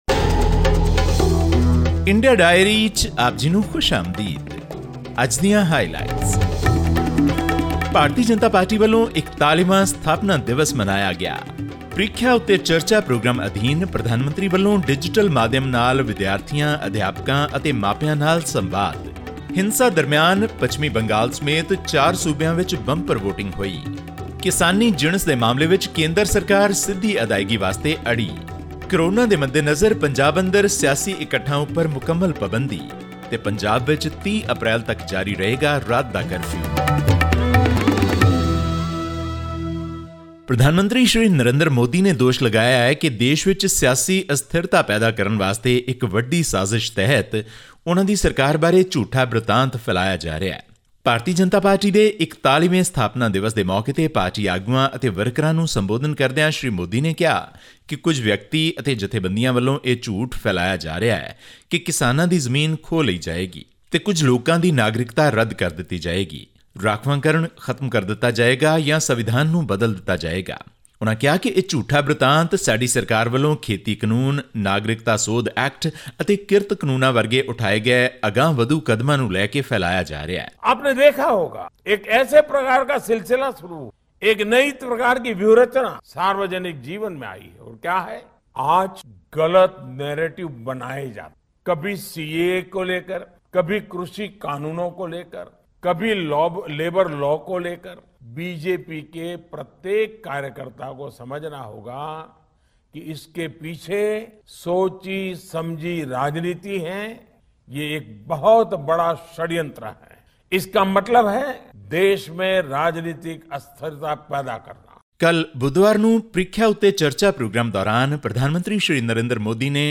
This and more in our weekly news segment from India.